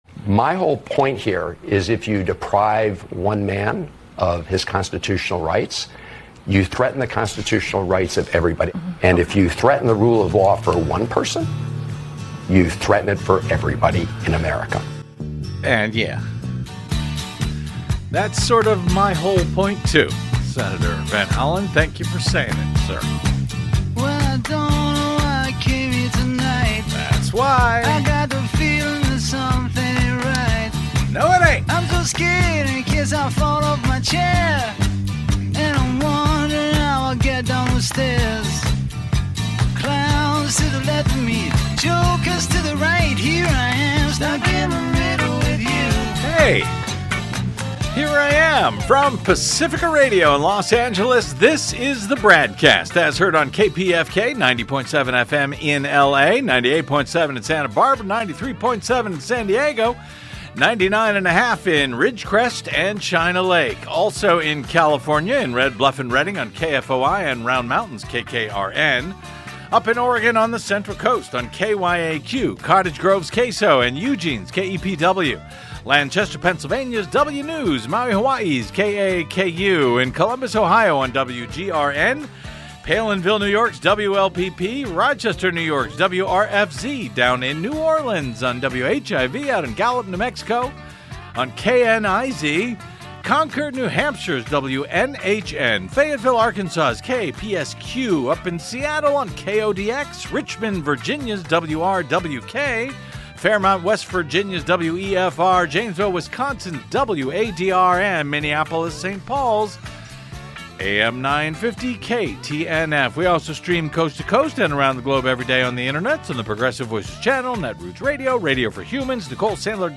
Catching up with the week that was; Admin nearing contempt charges; Conservatives now warning of Trump tyranny; Callers ring in...